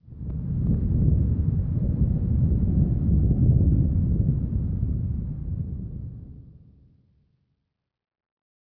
Ambient
rumble.wav